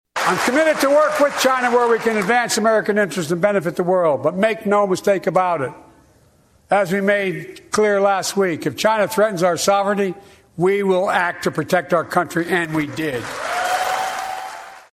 President Joe Biden delivered his second State of the Union address Tuesday night, discussing longstanding policy points and also mentioning recent world developments.